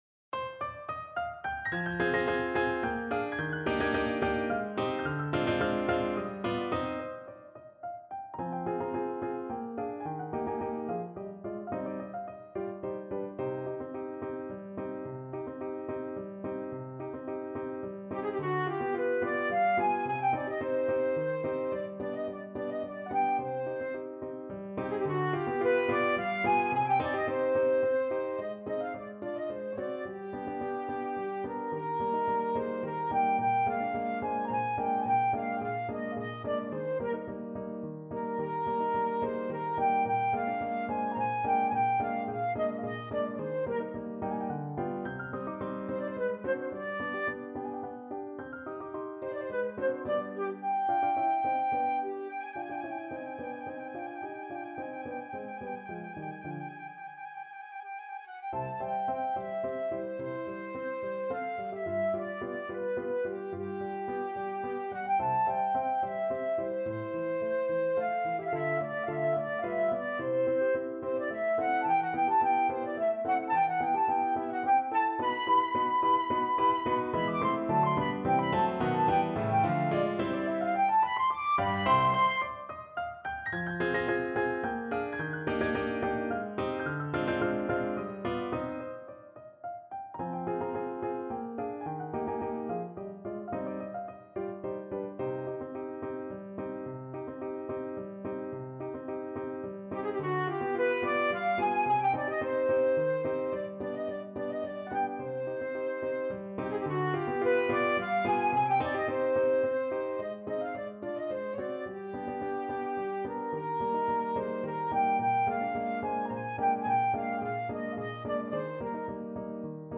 Classical Verdi, Giuseppe Merce Dilette Amiche from Les Vepres Siciliennes Flute version
Flute
3/4 (View more 3/4 Music)
Eb major (Sounding Pitch) (View more Eb major Music for Flute )
~ = 100 Allegro =108 (View more music marked Allegro)
Classical (View more Classical Flute Music)